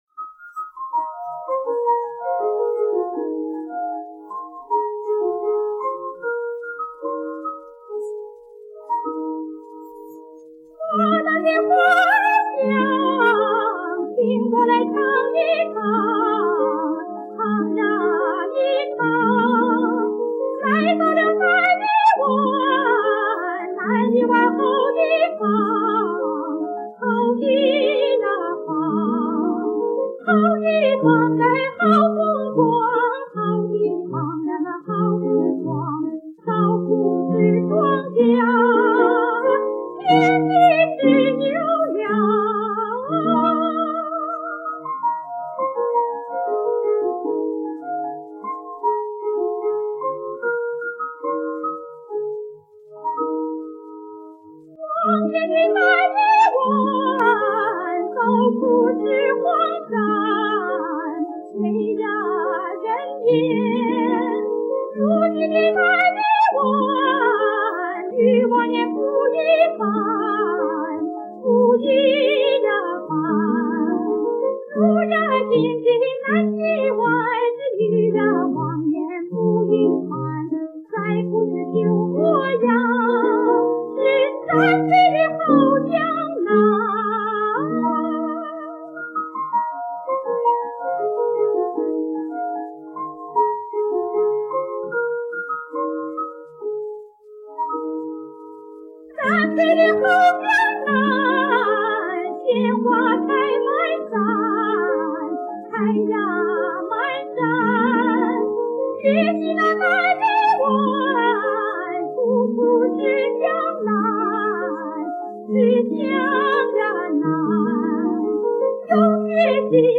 钢琴伴奏